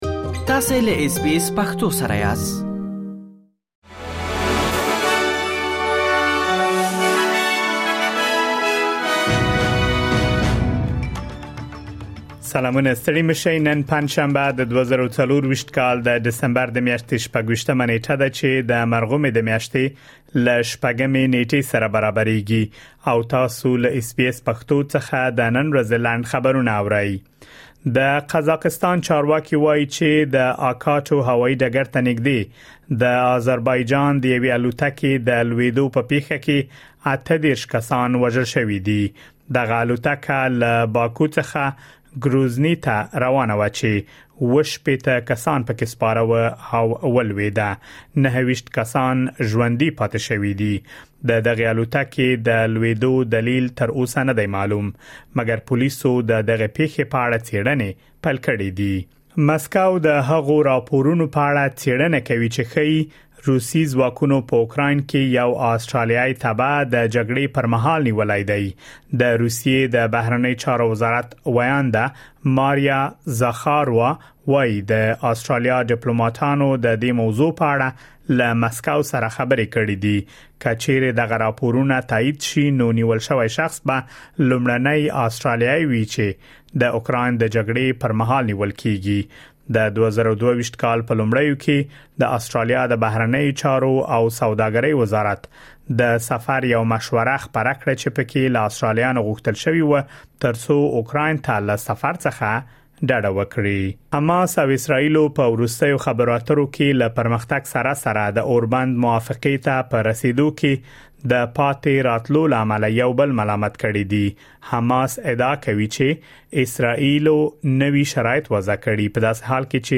د اس بي اس پښتو د نن ورځې لنډ خبرونه |۲۶ ډسمبر ۲۰۲۴